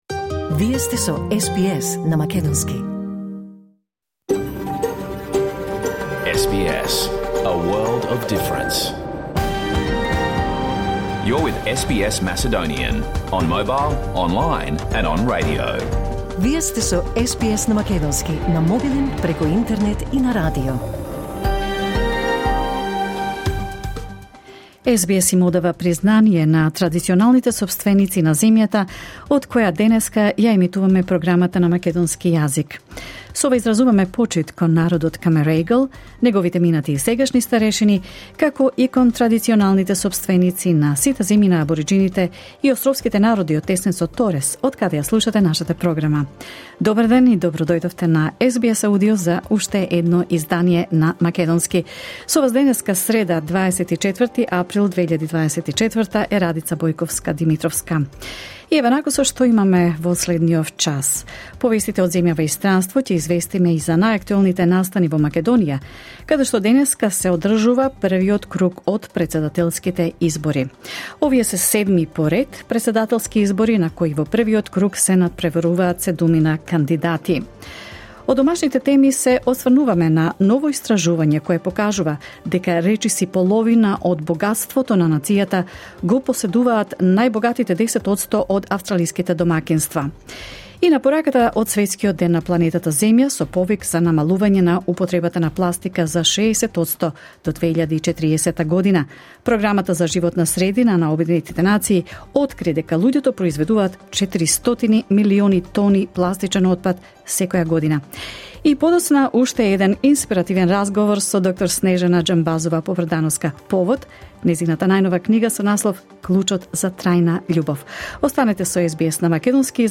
SBS Macedonian Program Live on Air 24 April 2024